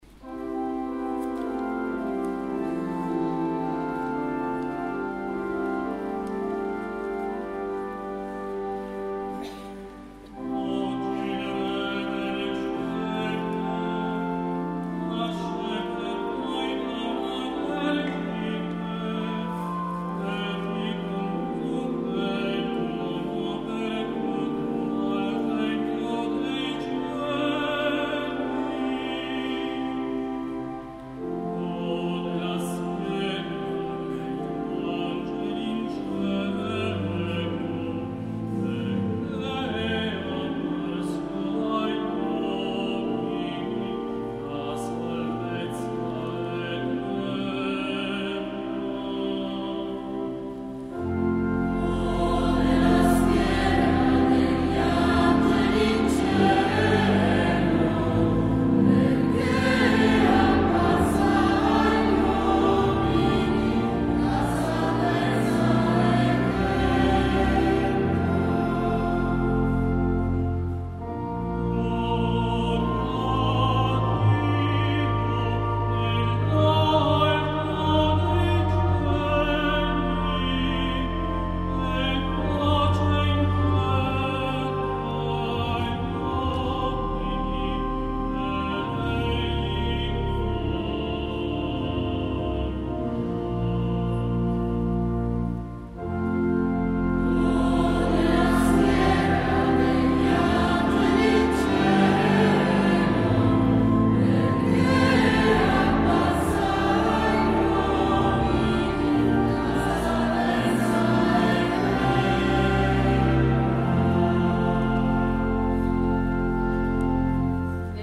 Gallery >> Audio >> Audio2015 >> Notte di Natale >> 08-Nat15-1Responsorio
08-Nat15-1Responsorio.mp3